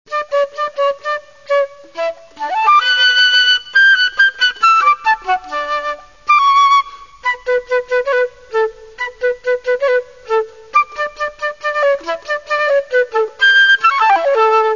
Flauta travessera